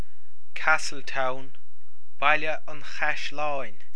Comhad Fuaime Foghraíochta